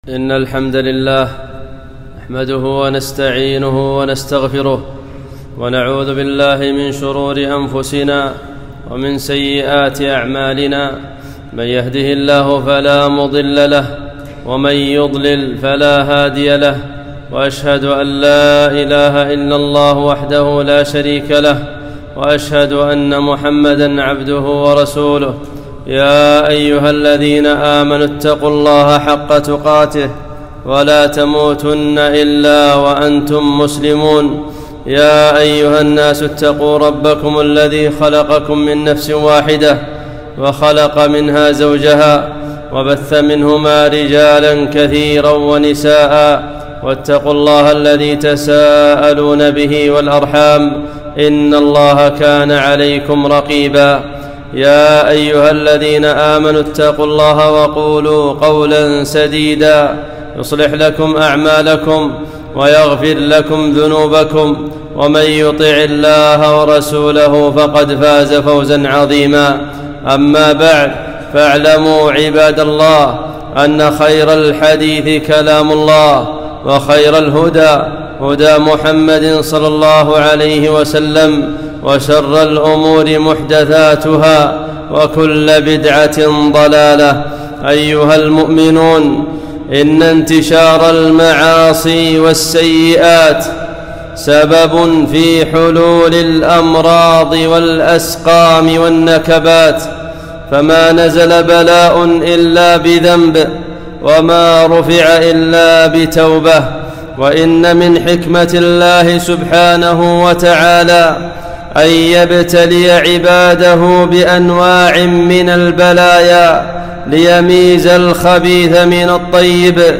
خطبة - داء كرونا